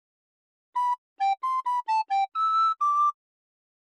リコーダーで吹くとこうなります↓↓